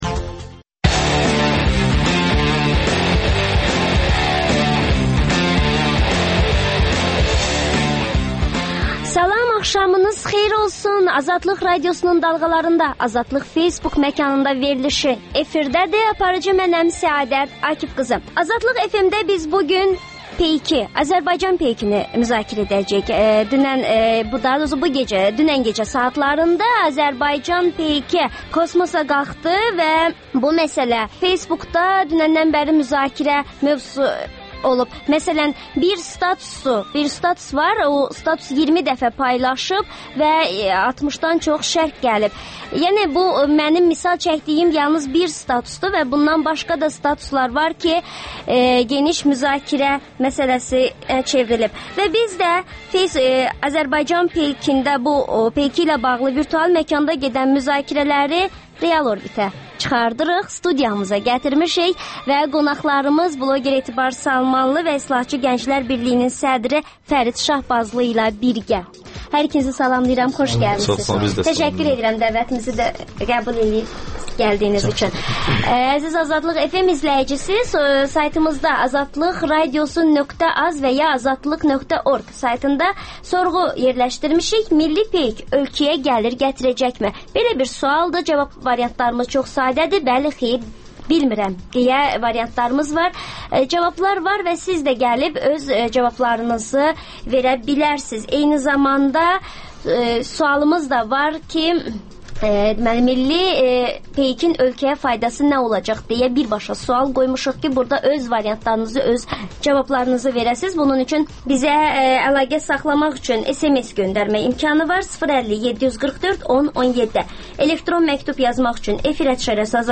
«Qaynar xətt» telefonunda dinləyicilərin suallarına hüquqşünaslar cavab verir.